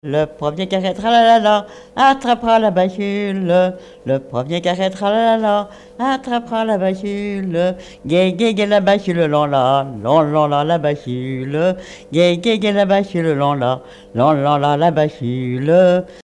Couplets à danser
danse : branle
Pièce musicale inédite